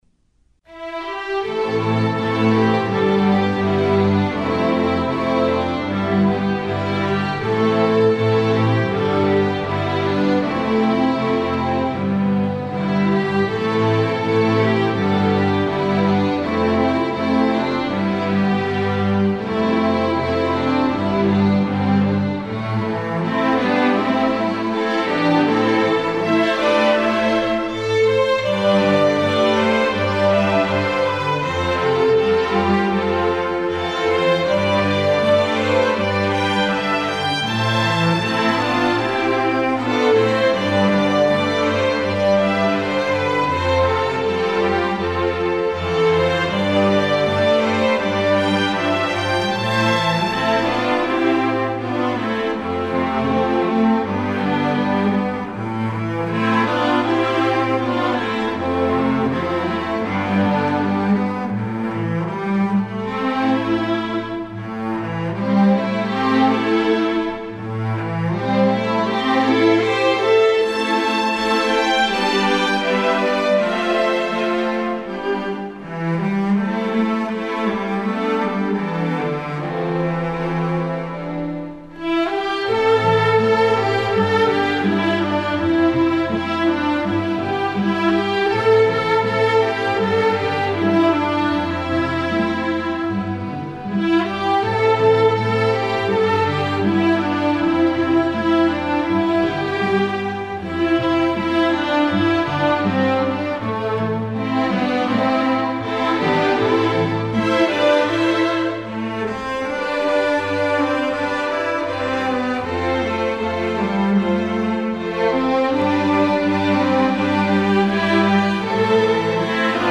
Voicing: String Orchestra